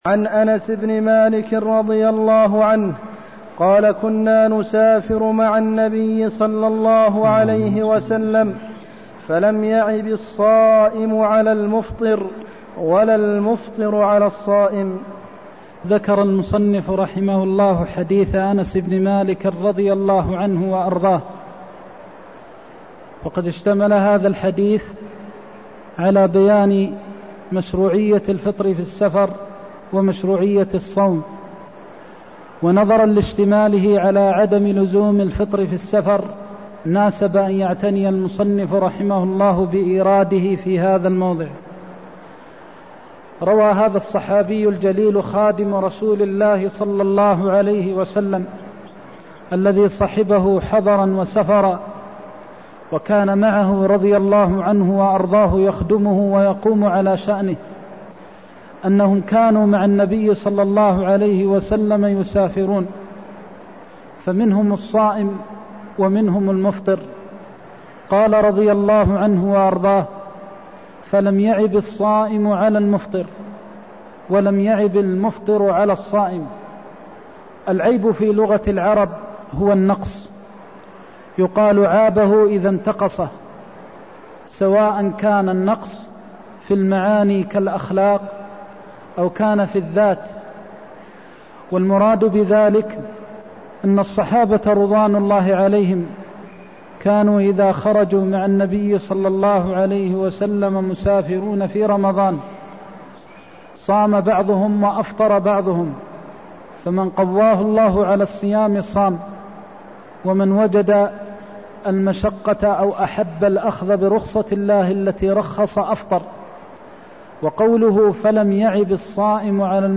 المكان: المسجد النبوي الشيخ: فضيلة الشيخ د. محمد بن محمد المختار فضيلة الشيخ د. محمد بن محمد المختار مشروعية الفطر أو الصوم في السفر (178)